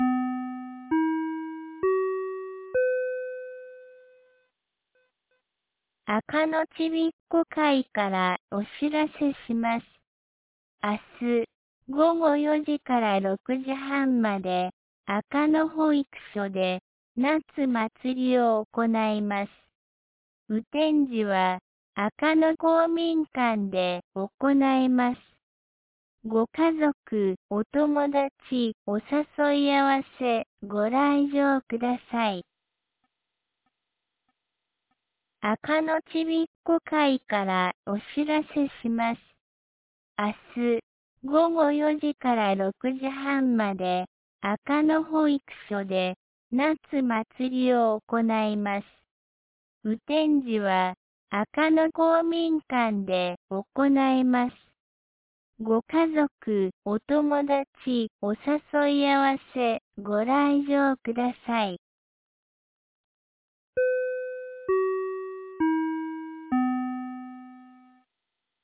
2025年07月11日 17時11分に、安芸市より赤野へ放送がありました。